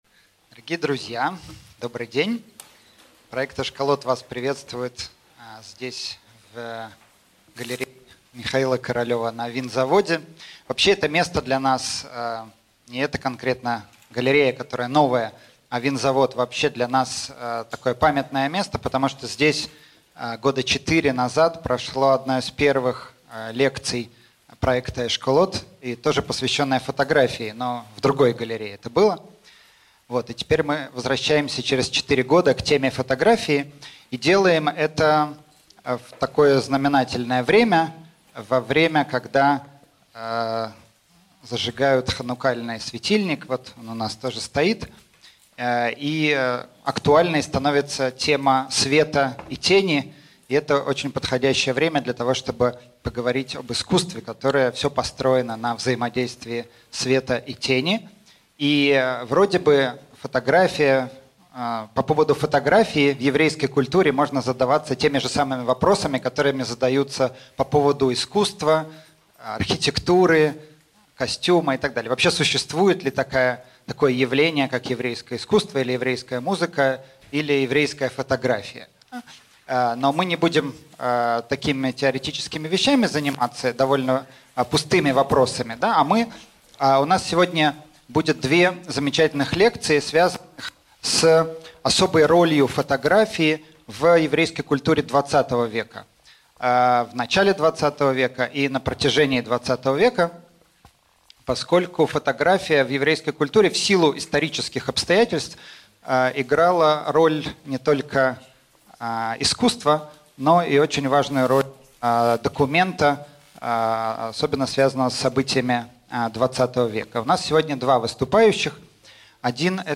Аудиокнига Фотография как искусство и документ | Библиотека аудиокниг